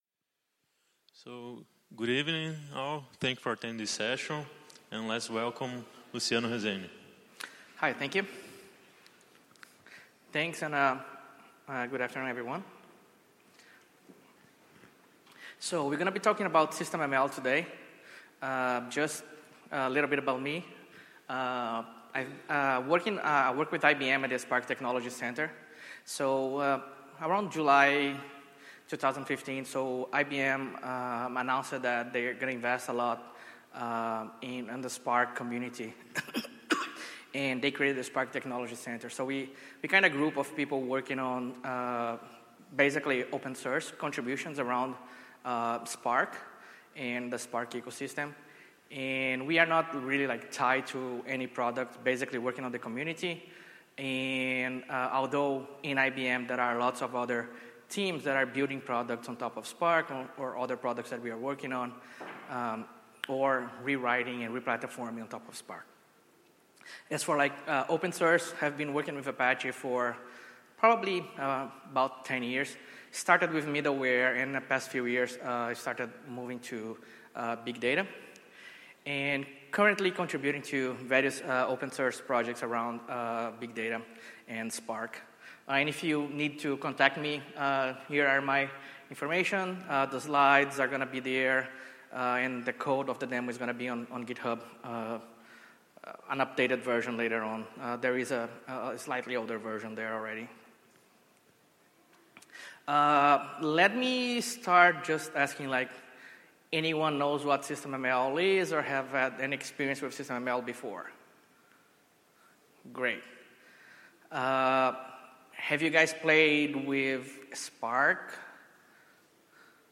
Apache Big Data Seville 2016